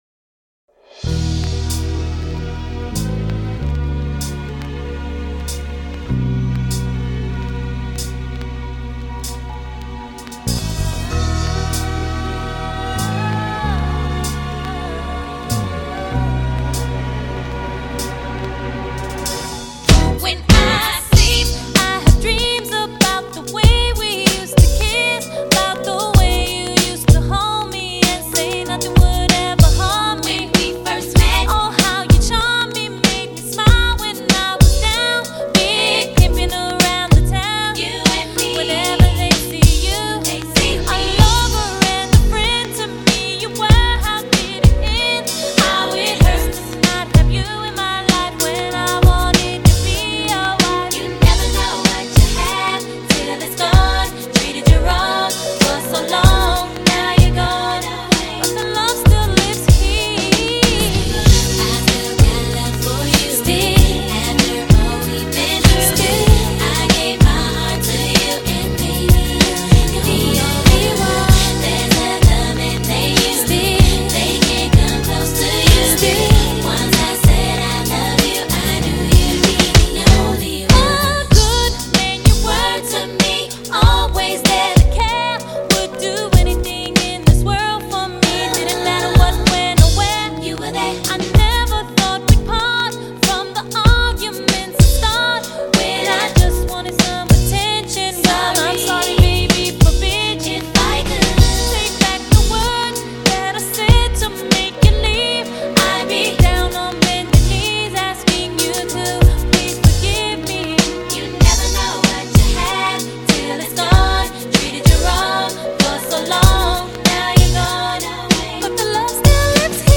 而她一贯轻薄纤细的温柔声线也较以往更为明显。